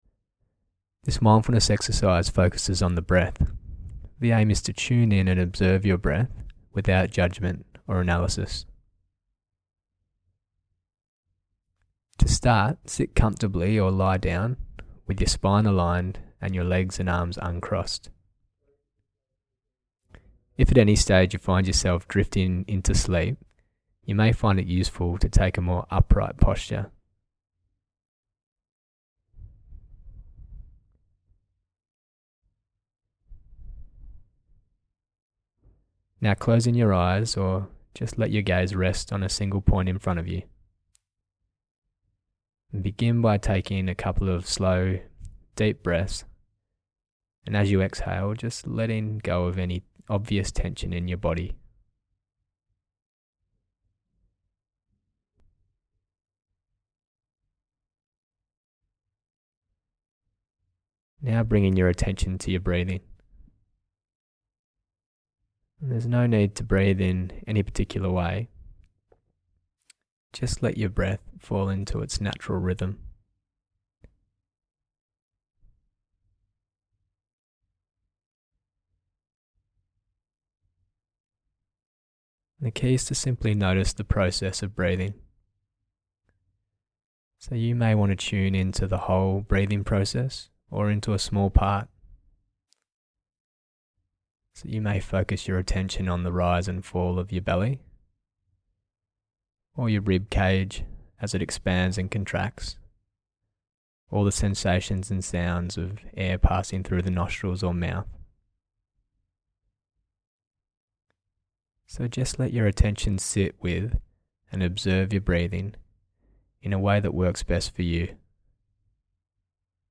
Mindfulness of the breath meditation practice